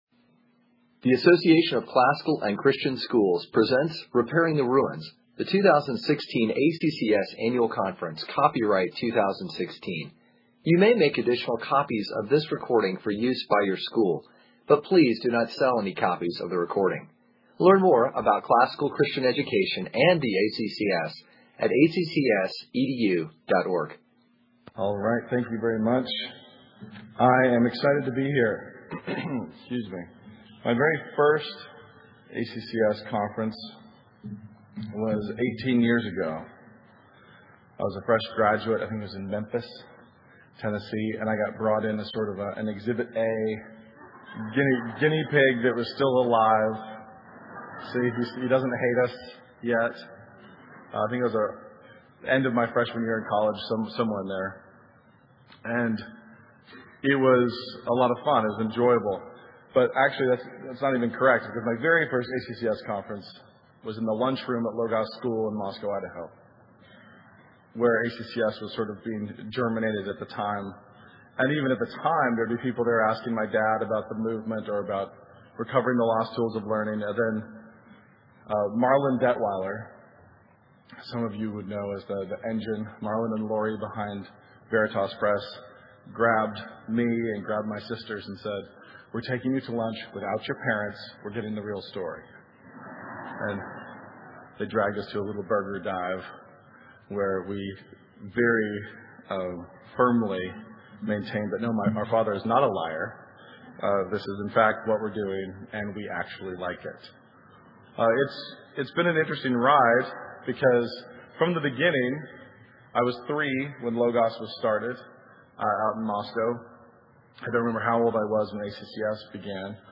2016 Workshop Talk | 0:52:29 | All Grade Levels, Art & Music